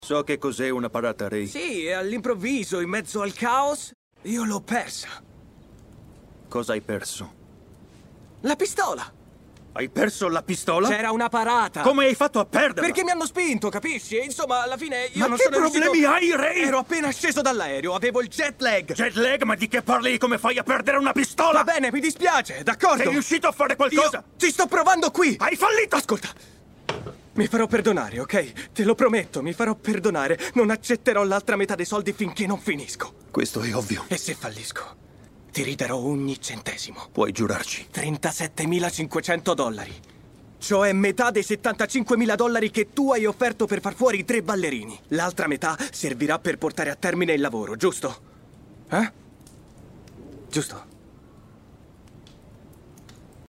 nel telefilm "Ecco a voi i Chippendales", in cui doppia Robin de Jesús.